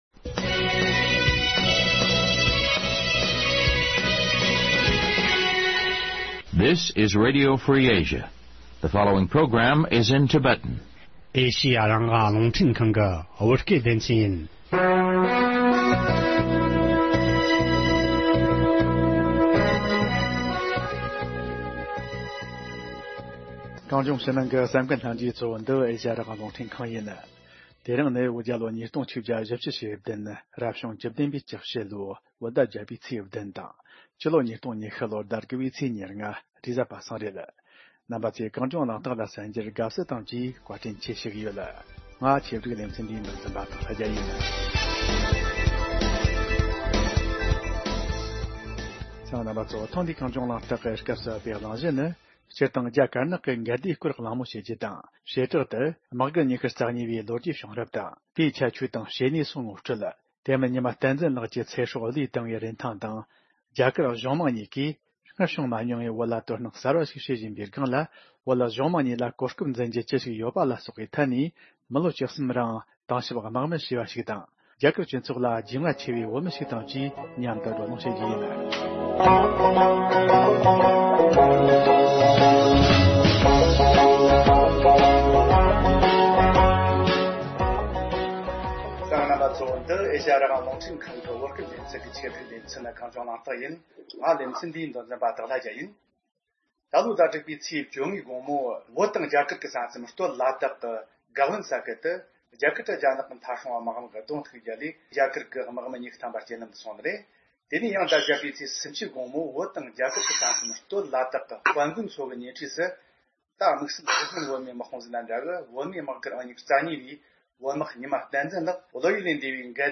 བགྲོ་གླེང་ཞུས་པ་གསན་རོགས་གནང་།